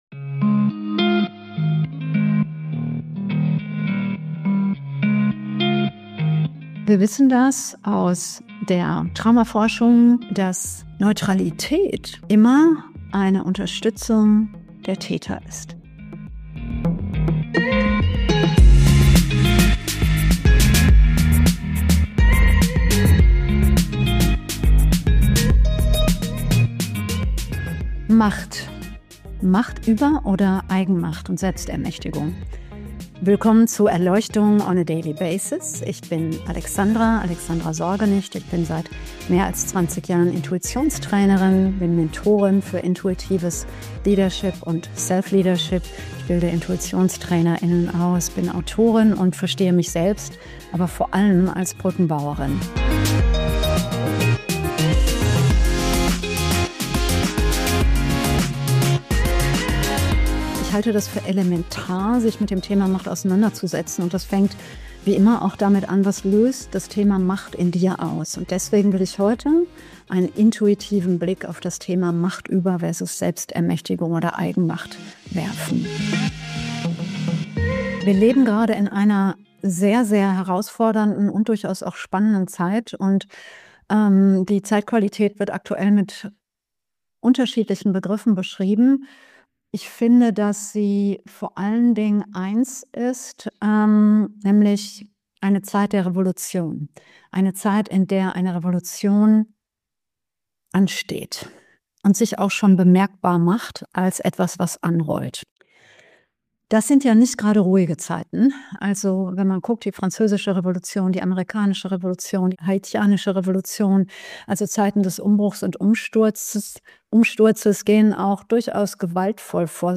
Intuitive Untersuchung/Meditation: ´13:20 - ´30:44 Podcast und Meditation mit Untersuchung deines eigenen Umgangs mit Macht.